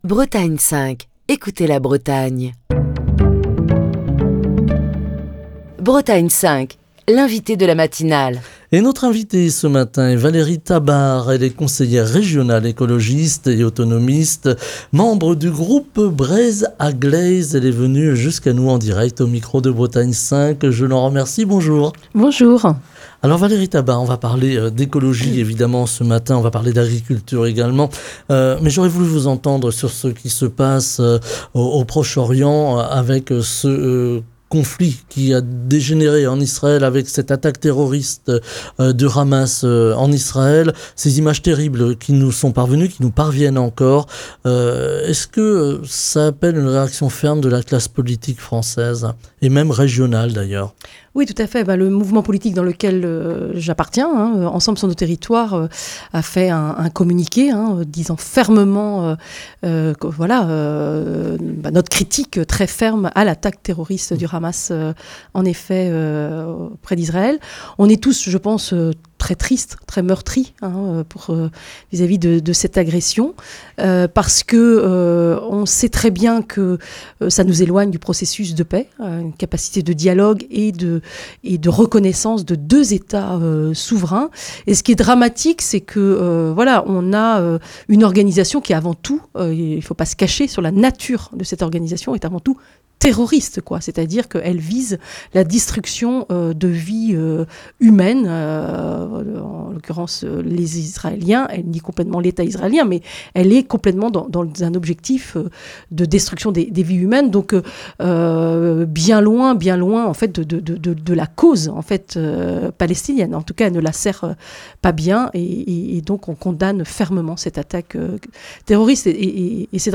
Valérie Tabart, conseillère régionale écologiste et autonomiste, membre du groupe Breizh a-gleiz | Bretagne5
Ce matin, nous parlons d'agriculture, d'écologie et plus largement de l'actualité dans la matinale de Bretagne 5. Beaucoup d'agriculteurs vont faire valoir leur droit à la retraite dans les années à venir, comment l'agriculture bretonne va s'adapter et comment la Région Bretagne peut-elle faciliter l'installation de jeunes agriculteurs.